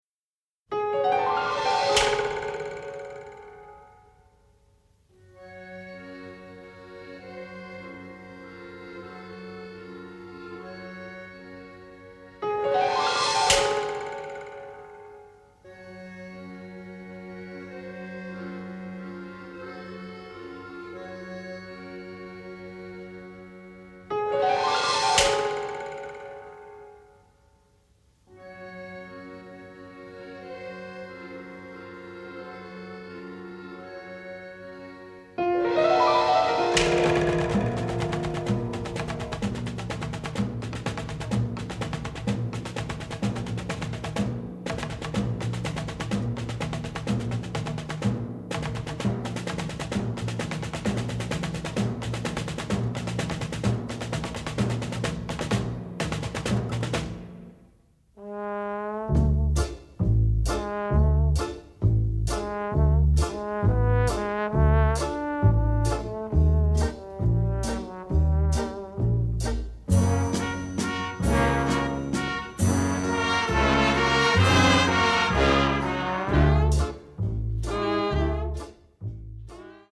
BONUS TRACKS (Mono)